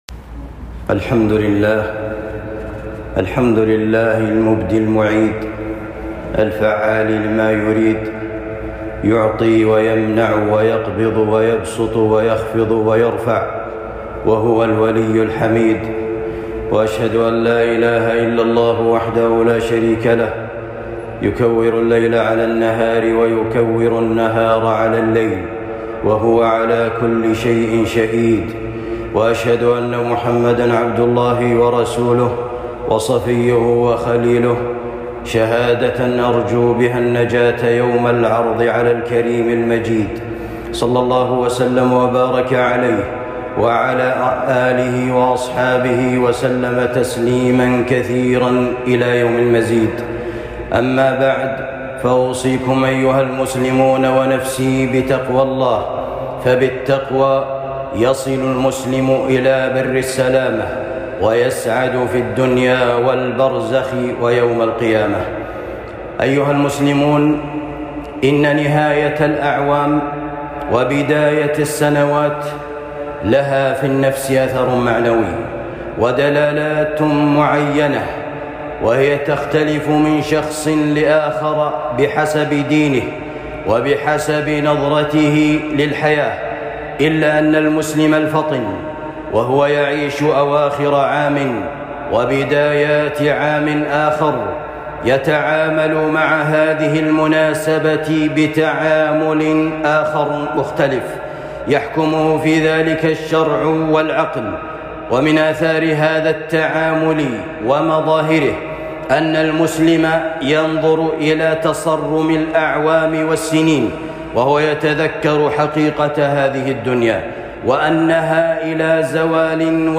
خطبة جمعة بعنوان وقفات مع نهاية عام جديد وبداية عام جديد